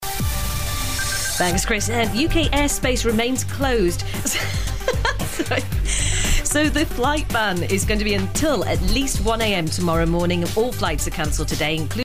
It's only travel news